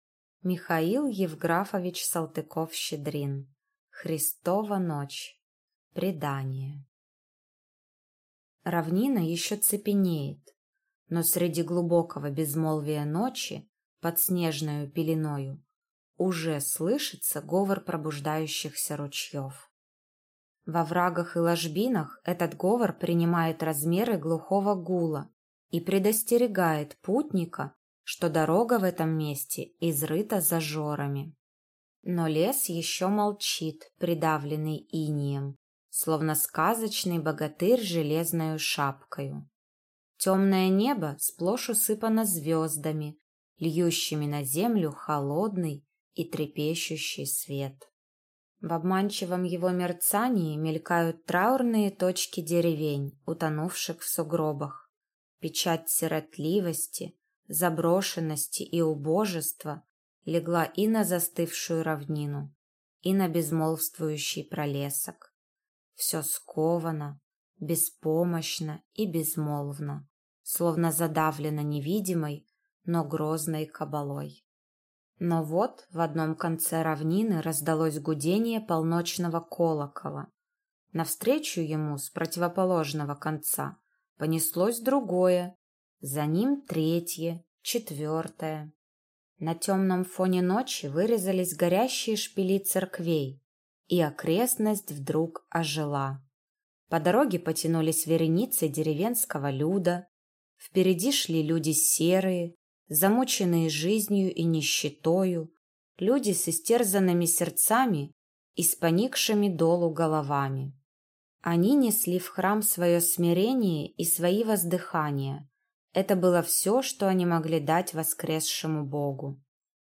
Аудиокнига Христова ночь | Библиотека аудиокниг